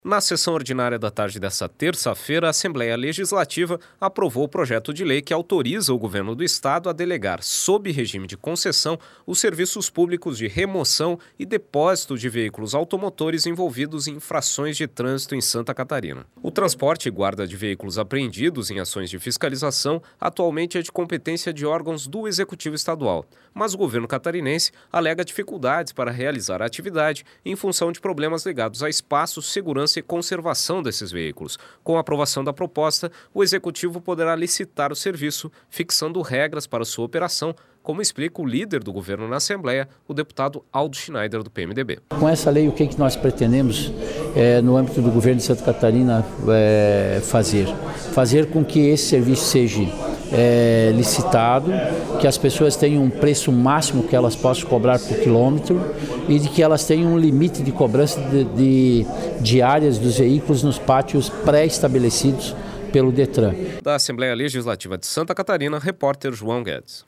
Entrevista com: deputado Aldo Schneider (PMDB), líder do governo na Assembleia Legislativa.